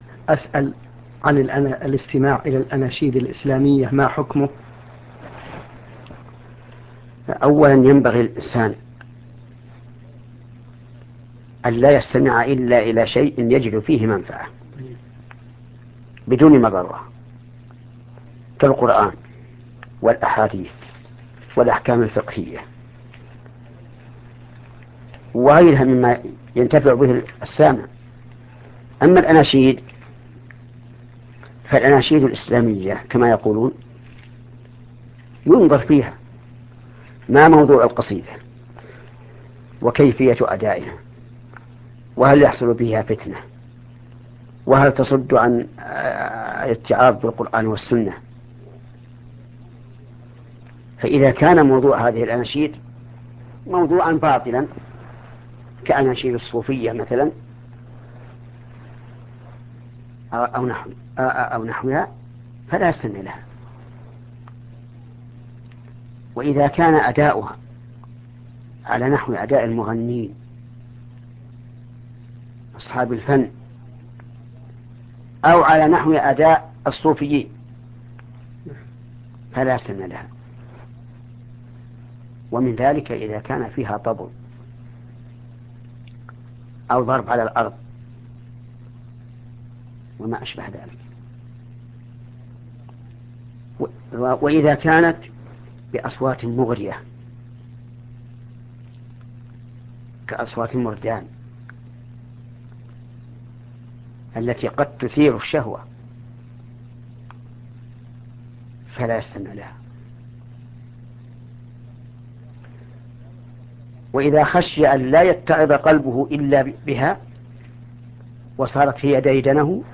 هذا السؤال موجه لـ لشيخ محمد بن صــالح العثيمين . . رحمه الله تعالى . .